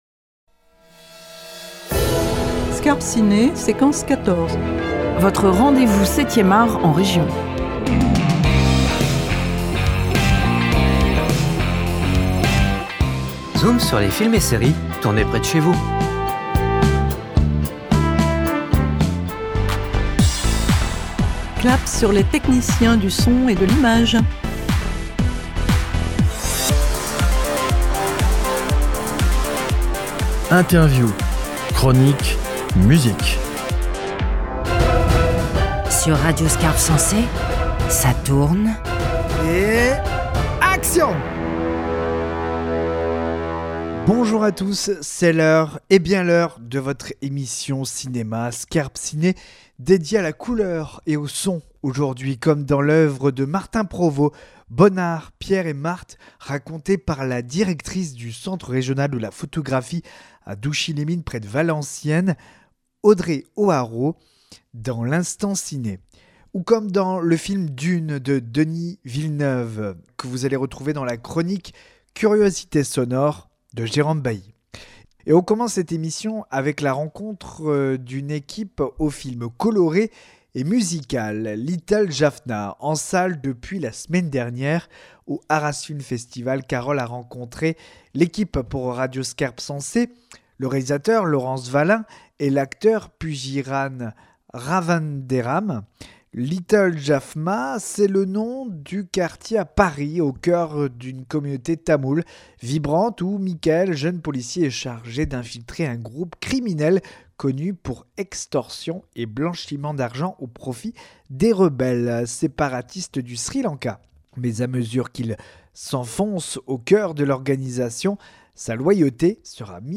Au Arras Film Festival